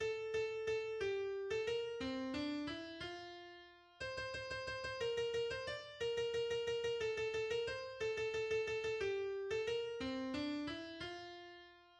Kanon